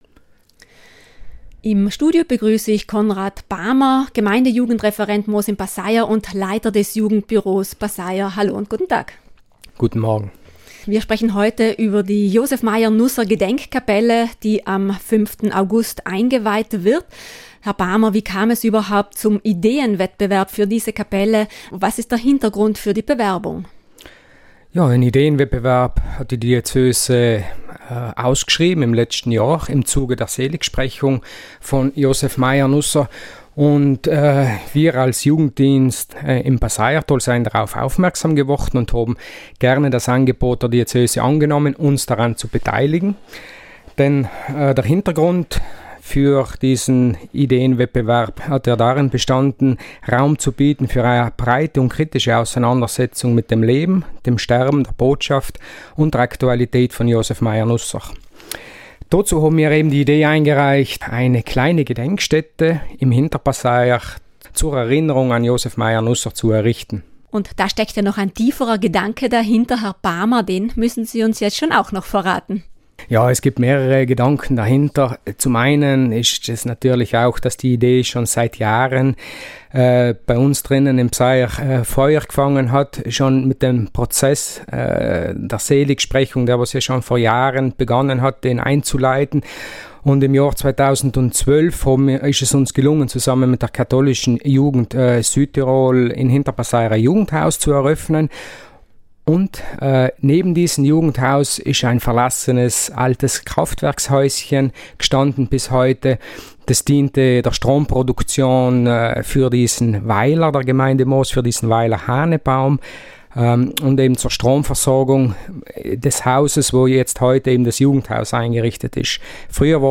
RADIOINTERVIEWS